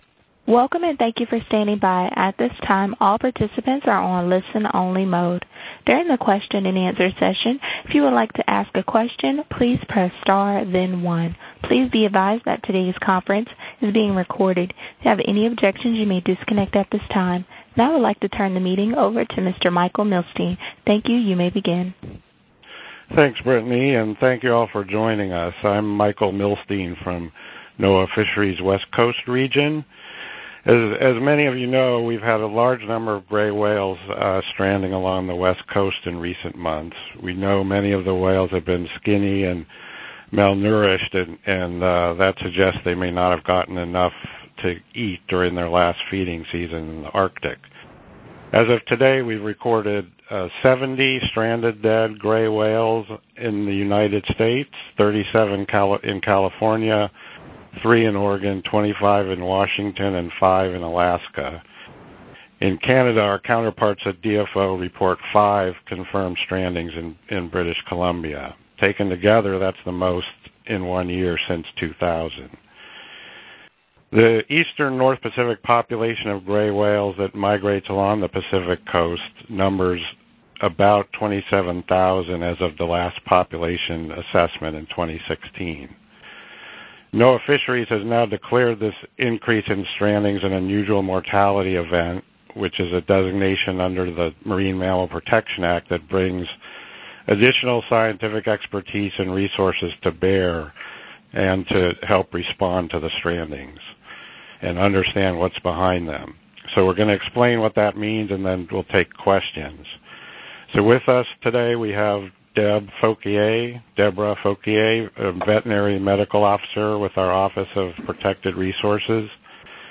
West Coast gray whale strandings declared an Unusual Mortality Event: Media teleconference May 31